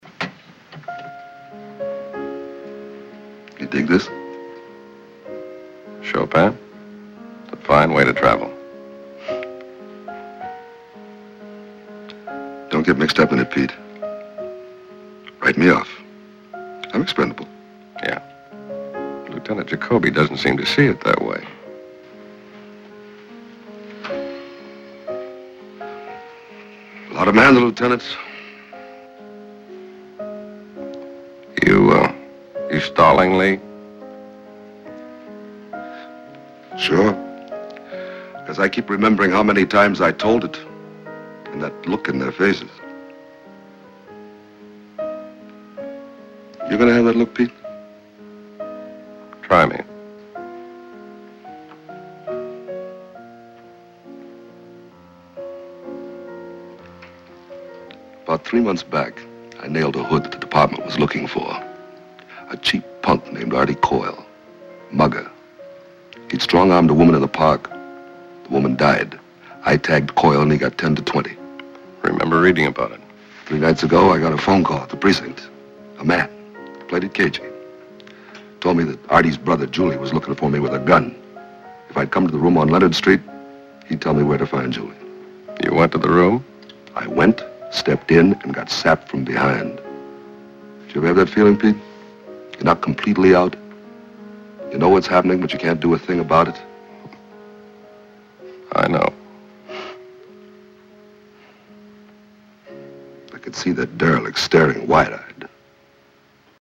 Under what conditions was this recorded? It is the same recording which does not follow the score exactly.